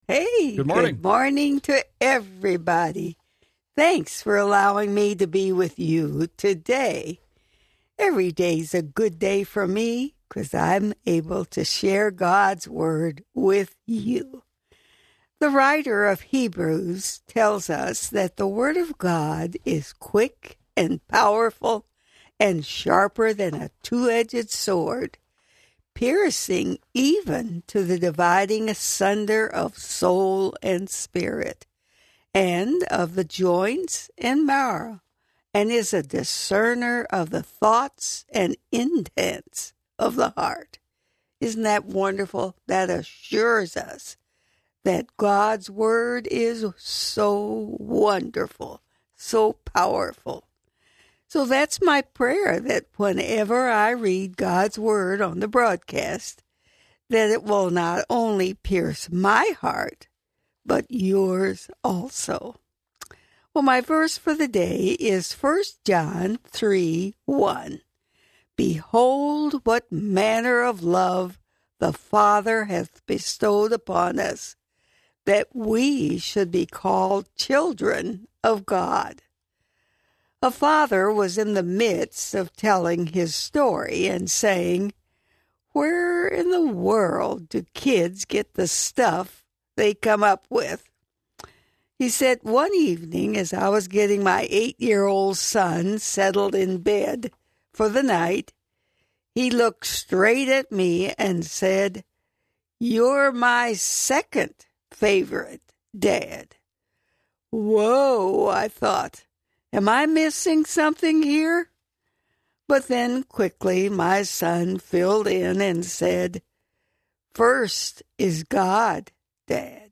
The show is heard weekdays live at 5:45 pm and 6:45 am on WWIB There have been plenty of stories of how the Covid shutdown of Major League baseball has affected the players, the owners and even those who work the MLB stadiums for the clubs, but very little has been mentioned about how the work stoppage has affected the Umpires.